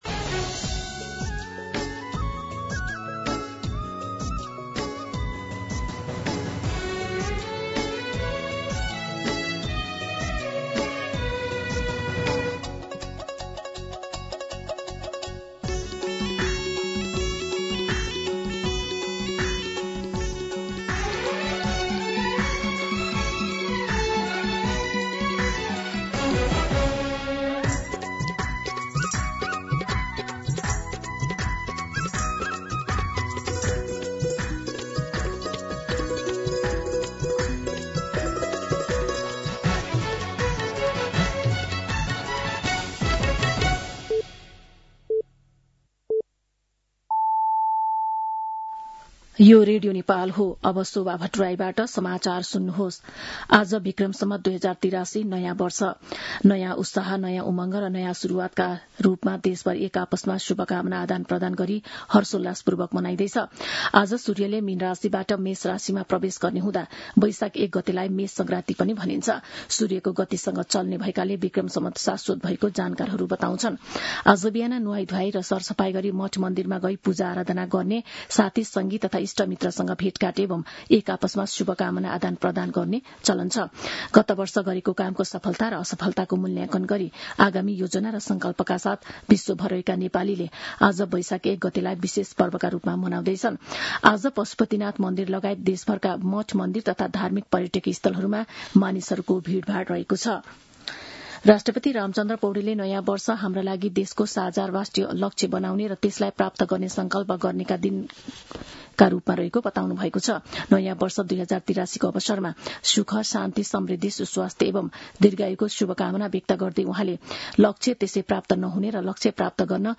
मध्यान्ह १२ बजेको नेपाली समाचार : १ वैशाख , २०८३